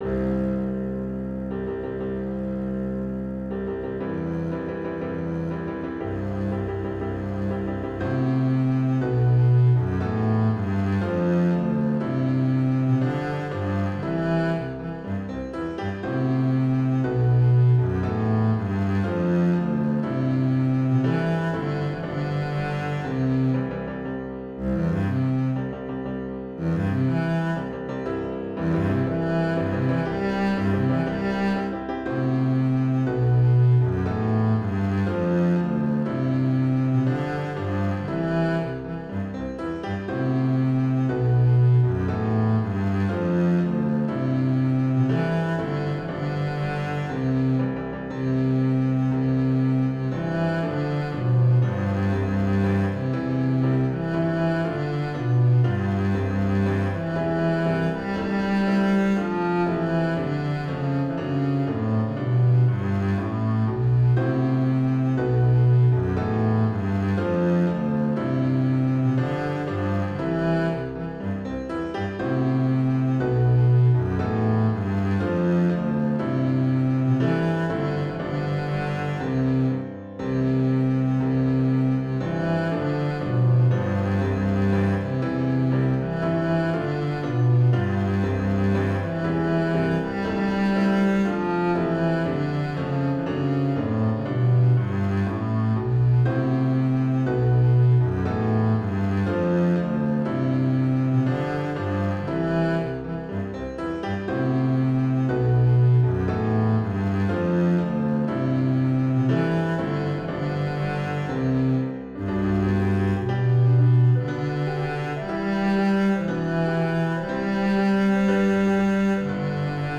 Intermediate Instrumental Solo with Piano Accompaniment.
Romantic Period, Wedding.
A ceremonial classic written for a double-bass solo.